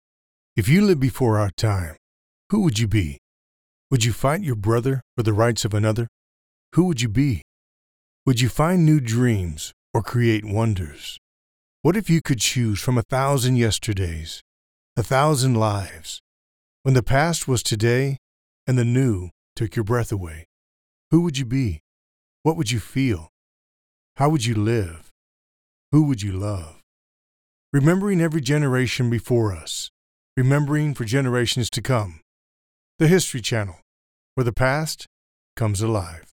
Male
Adult (30-50), Older Sound (50+)
A warm, conversational male voice with a clear, engaging delivery — ideal for explainer videos, eLearning, commercials, character work, and narration across a wide range of applications.
Television Spots
All our voice actors have professional broadcast quality recording studios.